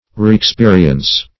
Search Result for " reexperience" : The Collaborative International Dictionary of English v.0.48: Reexperience \Re`ex*pe"ri*ence\ (-p?`r?-ens), n. A renewed or repeated experience.
reexperience.mp3